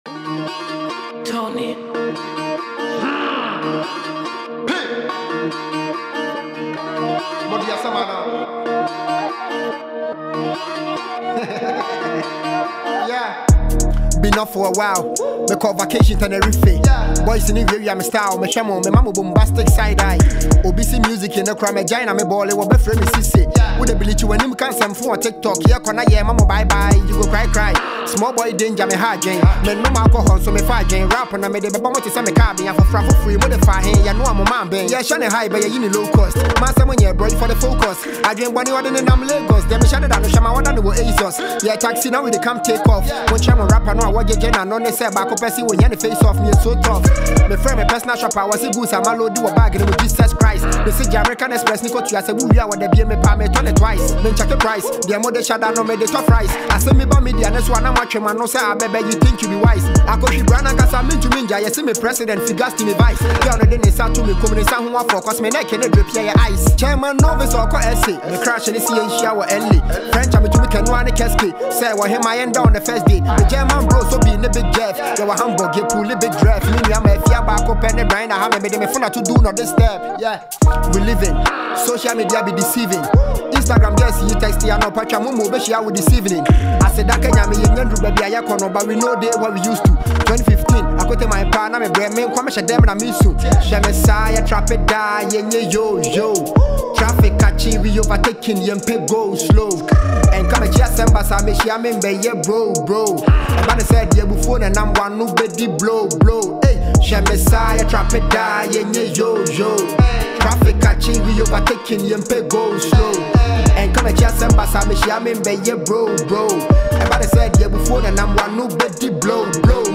Ghana Music
uptempo Asakaa Drill track
characterized by its energetic beats and engaging rhythms.